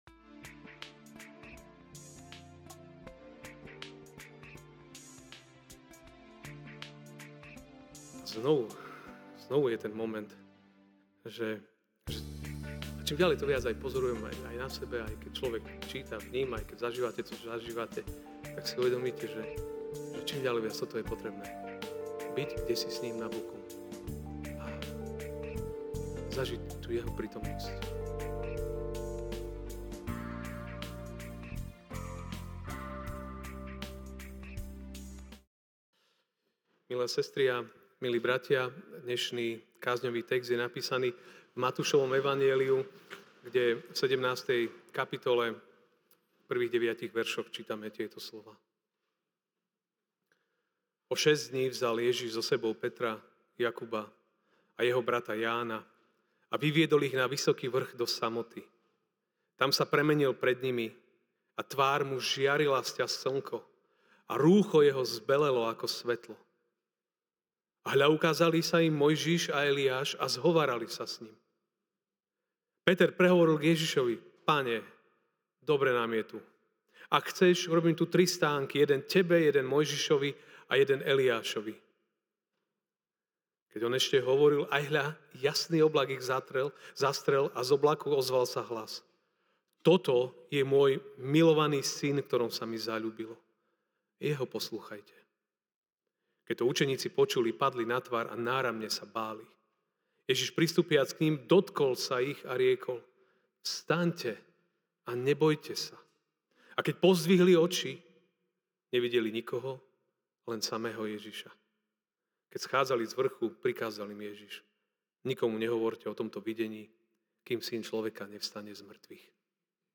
Ranná kázeň